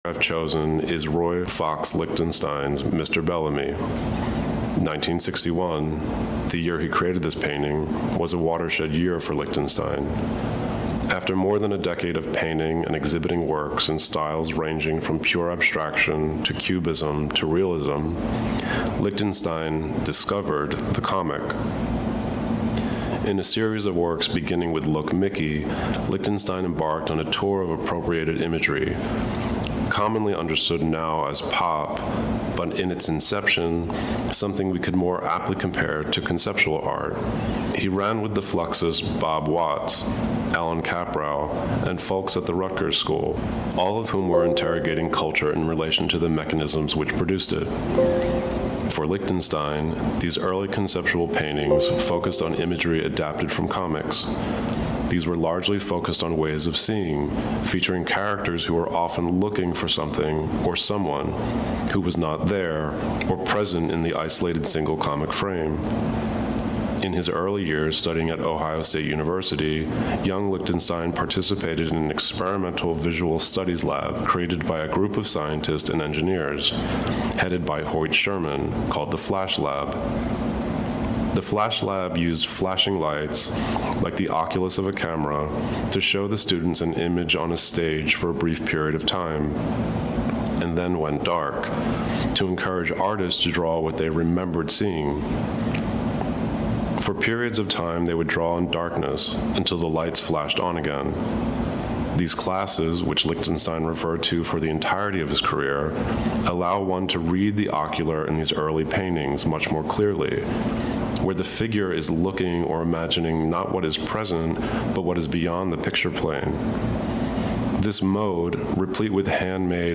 Clicking on it will let you listen to Da Corte himself as he comments on a work or on his own technique.